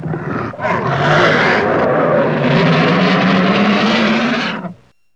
Index of /90_sSampleCDs/E-MU Producer Series Vol. 3 – Hollywood Sound Effects/Water/Alligators